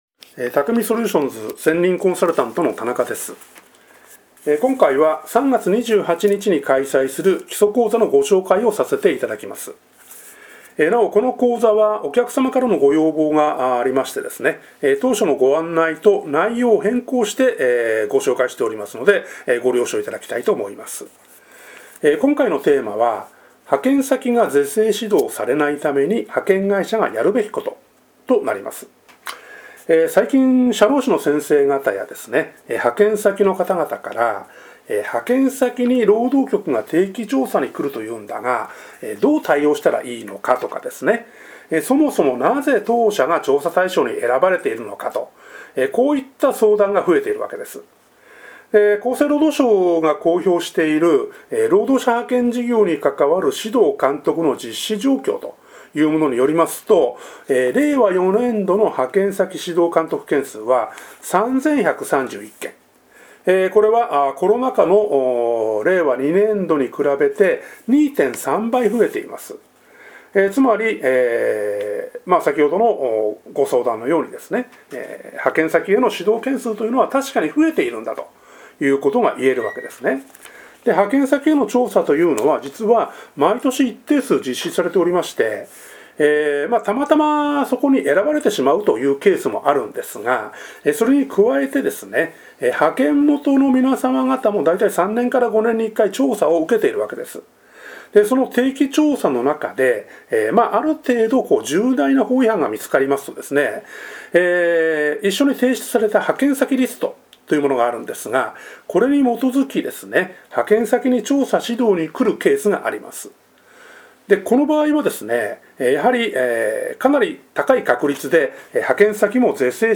音声解説